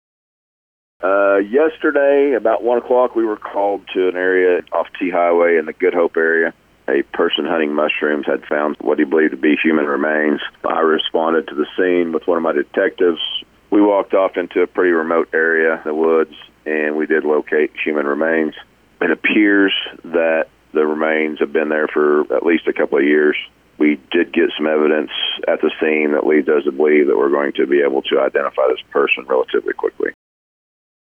Degase-Body-Statement.wav